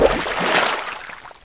watersplash.ogg